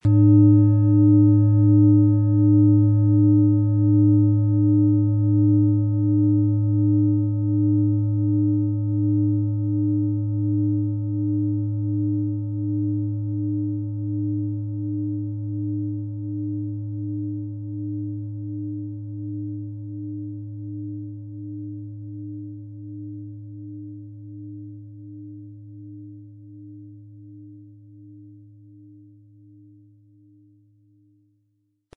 Tibetische Bauch-Kopf- und Herz-Klangschale, Ø 24,3 cm, 1400-1500 Gramm, mit Klöppel
Es ist eine von erfahrenen Meisterhänden in Handarbeit getriebene Klangschale.
Um den Original-Klang genau dieser Schale zu hören, lassen Sie bitte den hinterlegten Sound abspielen.
Im Lieferumfang enthalten ist ein Schlegel, der die Schale wohlklingend und harmonisch zum Klingen und Schwingen bringt.
MaterialBronze